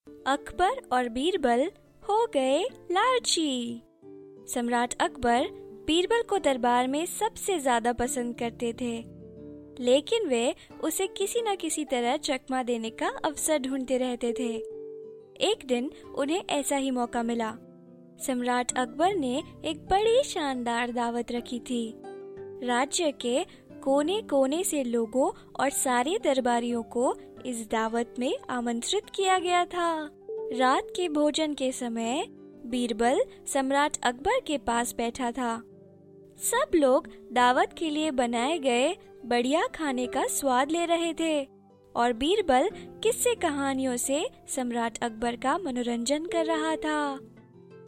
Female Professional Bilingual Voice Over Artist ( English/ Hindi )
indisches engl.
Sprechprobe: eLearning (Muttersprache):